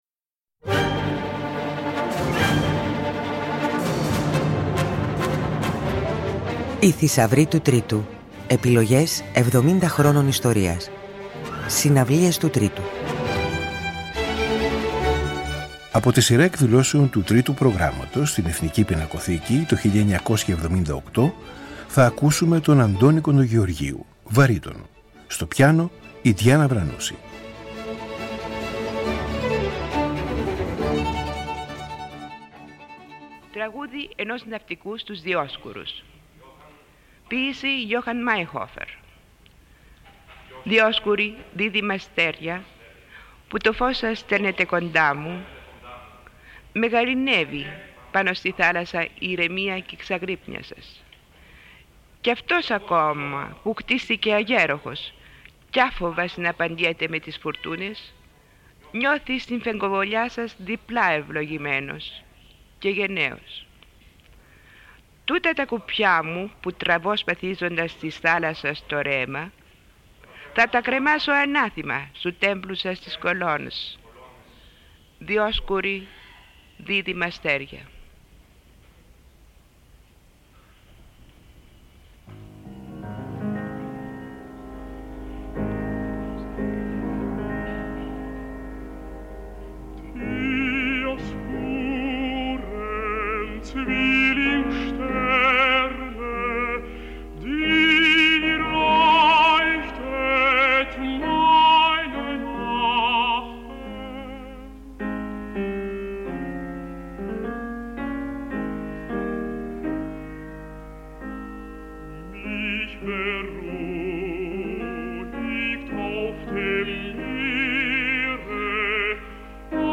Από ζωντανή ηχογράφηση ακούστε το ρεσιτάλ
βαρύτονου
τραγούδια-μικρά «διαμάντια» του κλασικού ρεπερτορίου
πιάνο